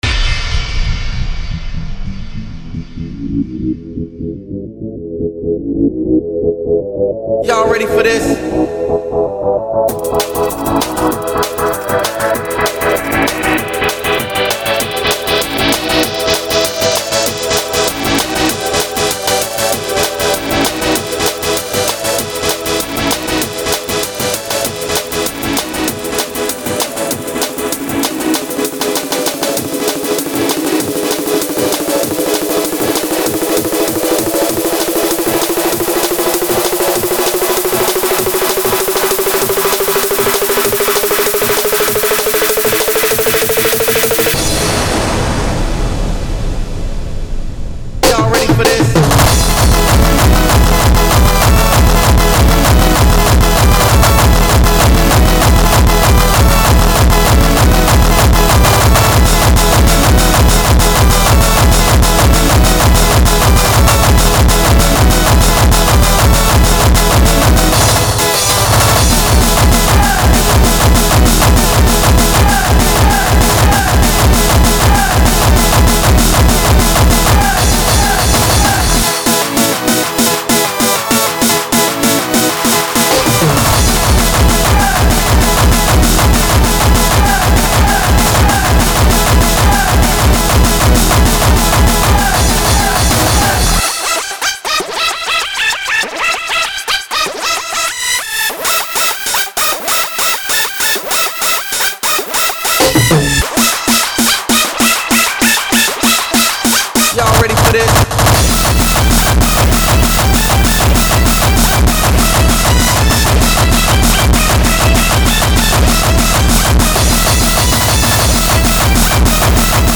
SORRY I CAN'T HEAR YOU OVER THE SCREAMS OF THE AUDIENCE.